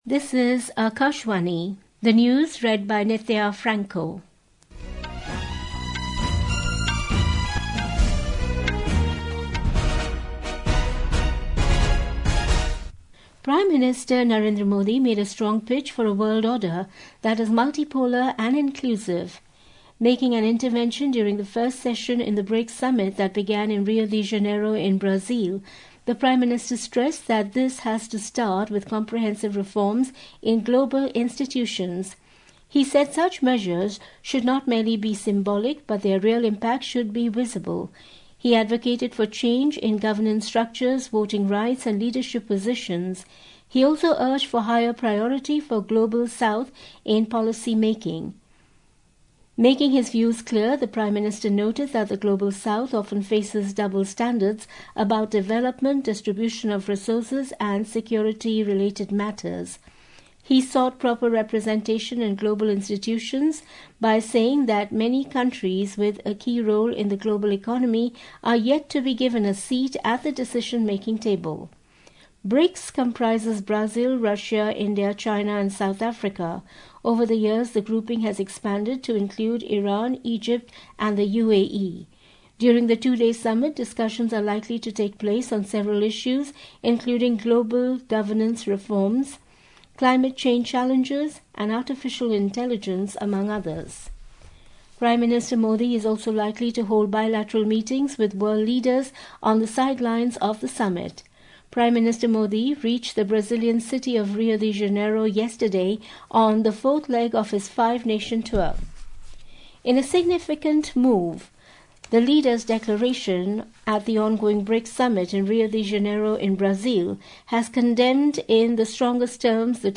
Hourly News
Hourly News | English